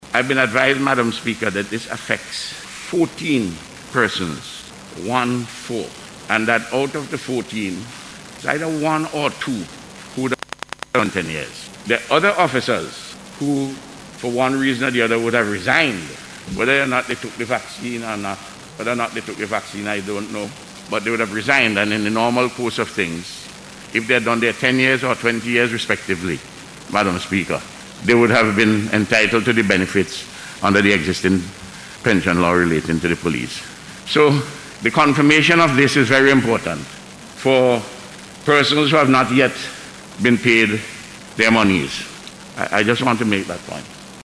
A resolution calling for the approval by Parliament of the Covid 19 (Miscellaneous Amendments) Order 2021 (No. 32 of 2021); was debated by Members of Parliament during this morning’s session of Parliament.